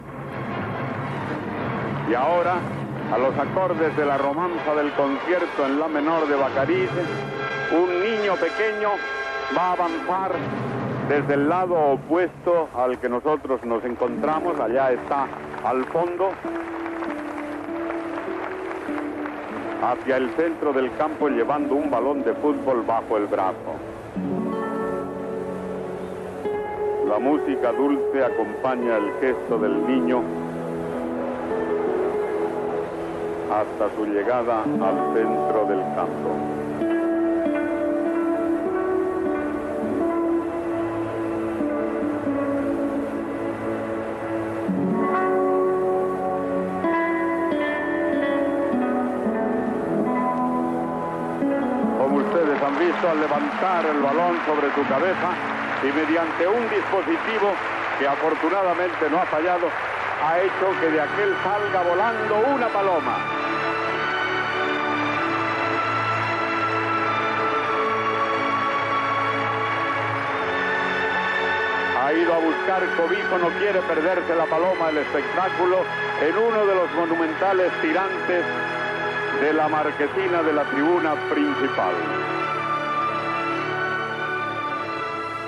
Transmissió de la cerimònia inaugural del Mundial de Futbol de 1982 des del Nou Camp de Barcelona.
Informatiu
La transmissió de RNE la van fer Luis del Olmo i Matías Prats.